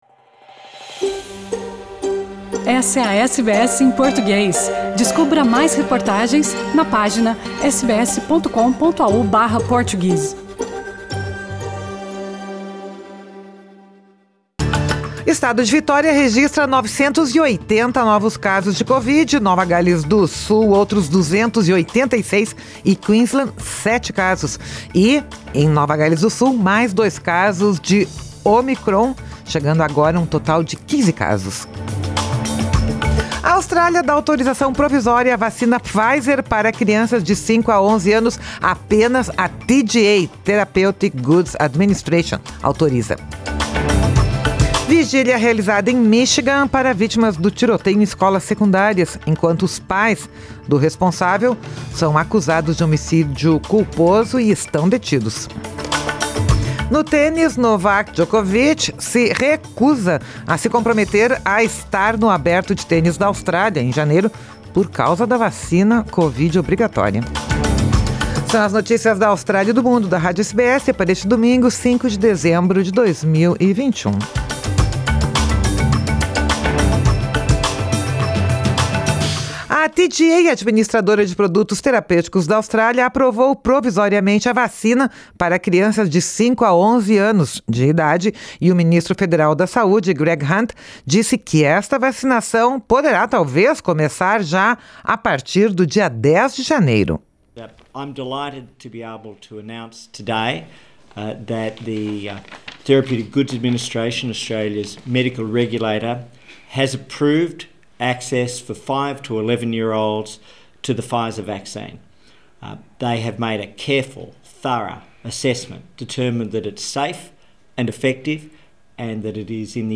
E no tênis, Novak Djokovic se recusa a se comprometer com o Aberto de Tênis da Austrália de janeiro, por causa da vacina obrigatória. São as notícias da Austrália e do Mundo da Rádio SBS para este domingo, 5 de dezembro de 2021.